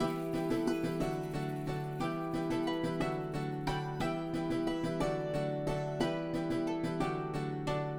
E minor to D Major
Guests remarked that the minor to major version sounded like a market, or somewhere in the dessert, grounded by feelings of going somewhere.